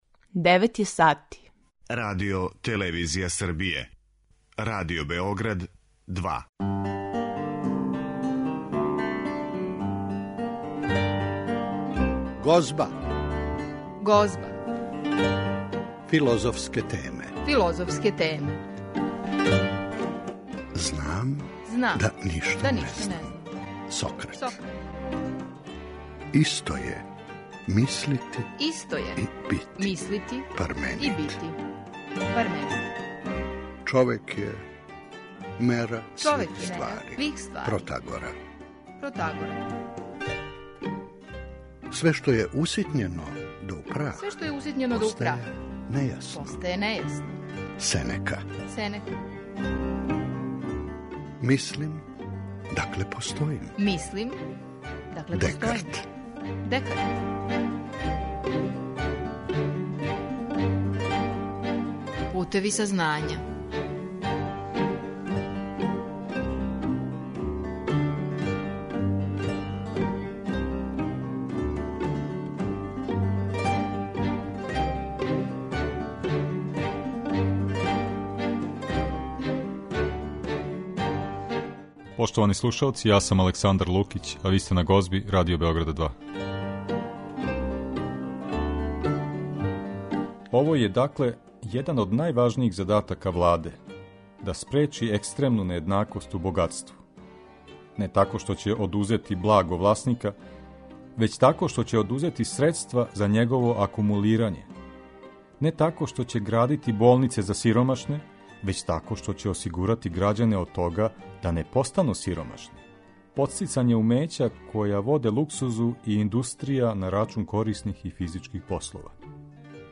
Емисија се бави филозофским темама